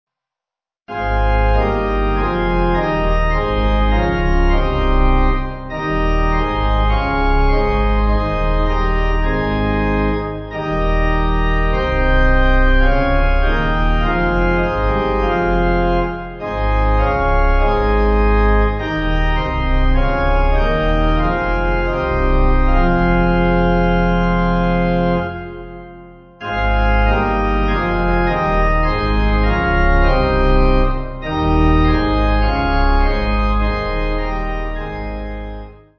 (CM)   4/Fm